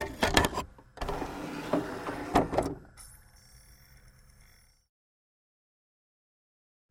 Звуки CD-плеера
Процедура вставки диска